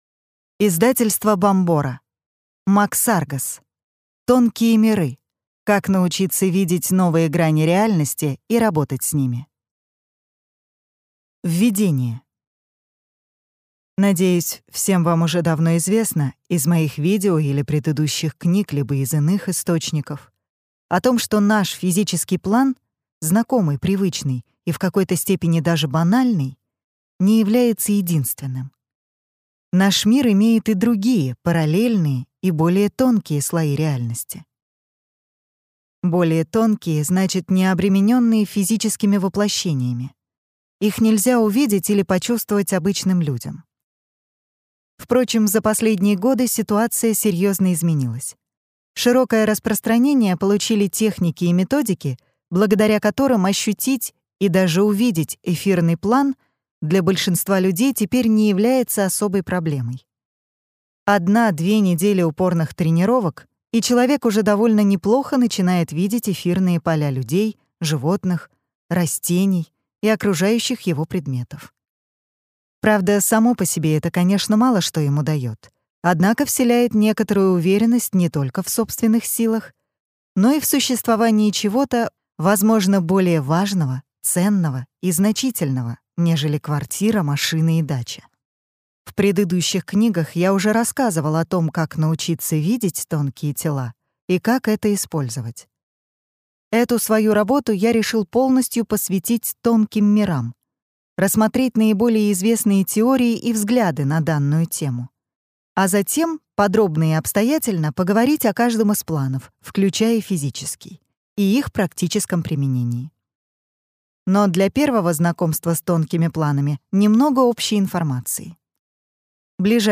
Аудиокнига Тонкие миры. Как научиться видеть новые грани реальности и работать с ними | Библиотека аудиокниг